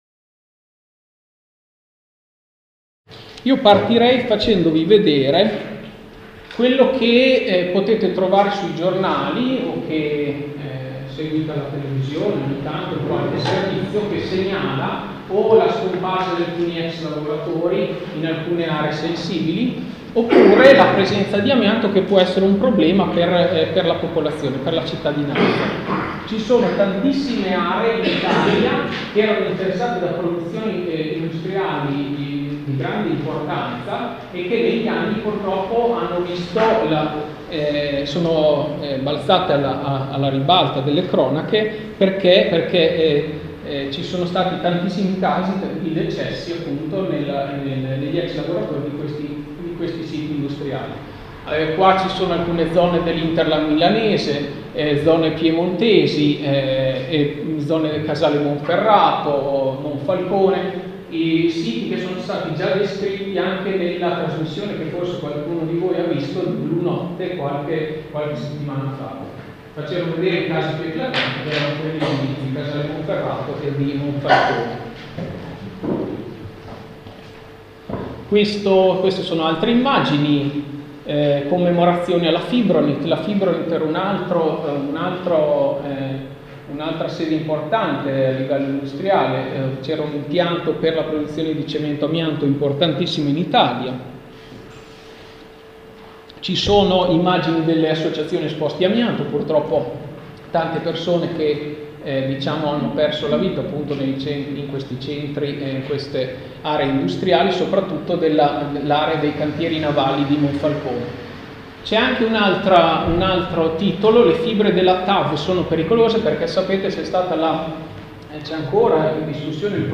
Conferenza organizzata, grazie alle Guardie ecologiche Volontarie di Legambiente di Modena, su questo pericoloso materiale.